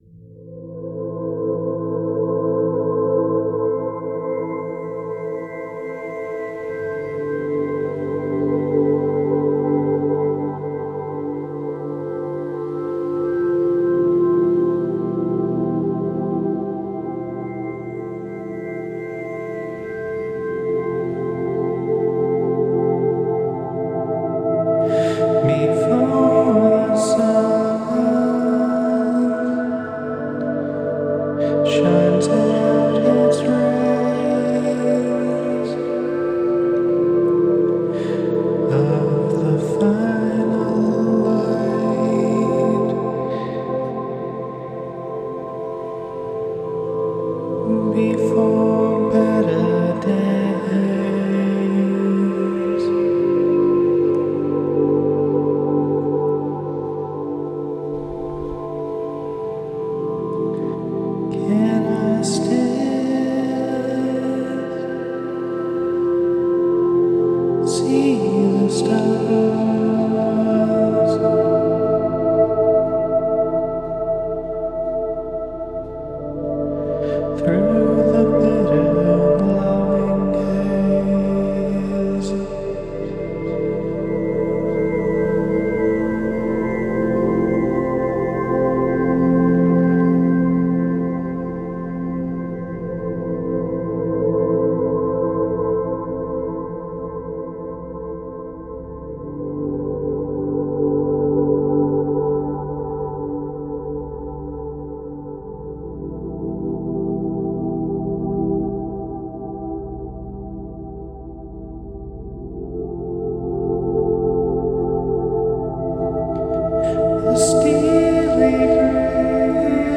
Love the piano and string combo, you always such lovely cinematic writing, i love it. The chords in this one especially are good, it's very full of nice 7th's and suspensions. Super into the mallet sounds too, it's very lovely and well paced, and a lot of awesome melodies.
an emotional piece on catastrophe.